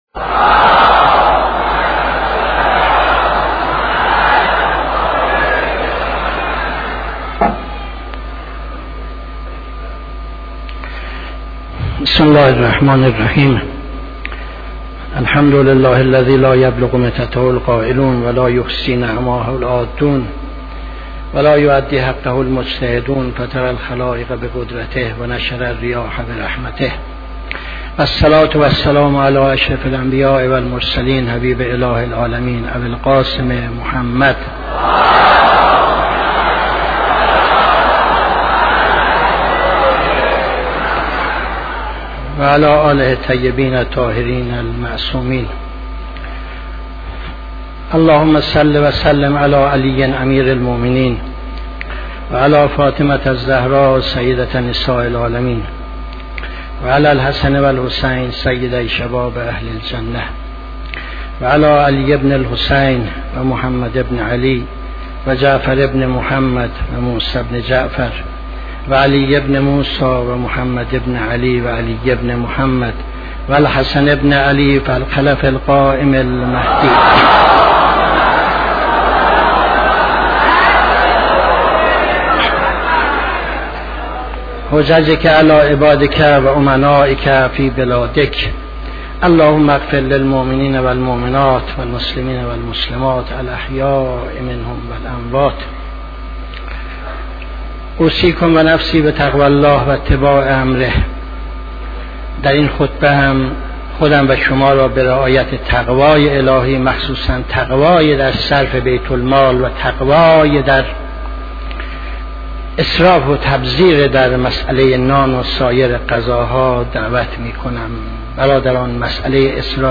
خطبه دوم نماز جمعه 15-01-76